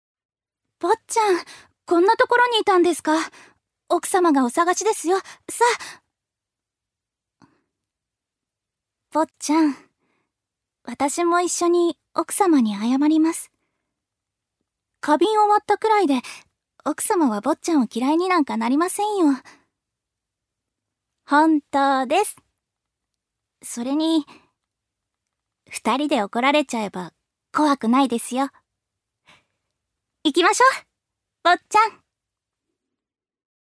預かり：女性
セリフ３